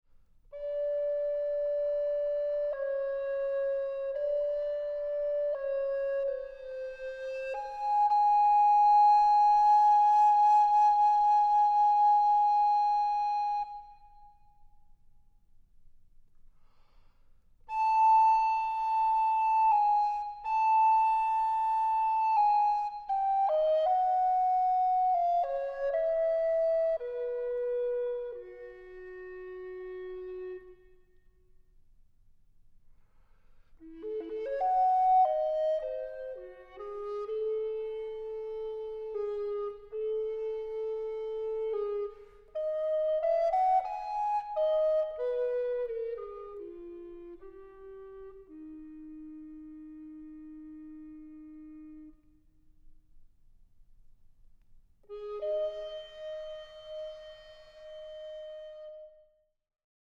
Flöte
Aufnahme: Deutschlandfunk Kammermusiksaal, Köln, 2023 + 2024